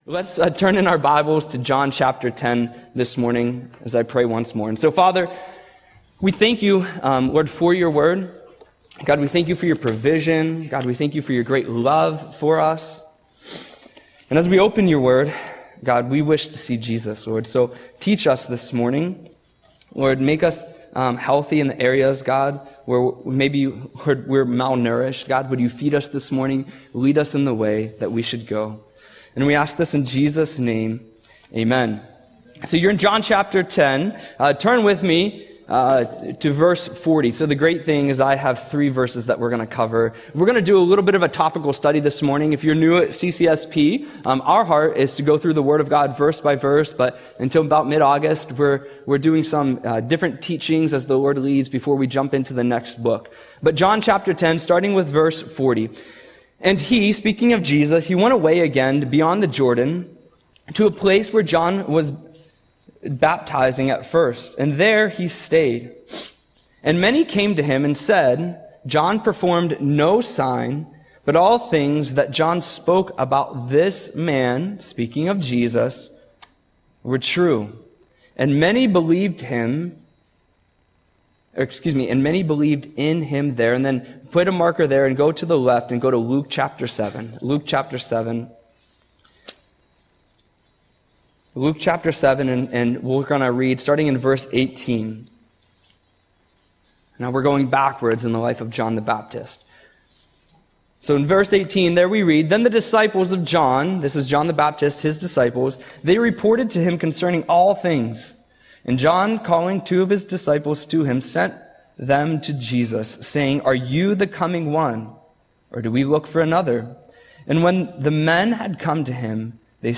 Audio Sermon - July 20, 2025